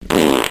Loud Fart Sound Effect Download: Instant Soundboard Button
Loud Fart Sound Button - Free Download & Play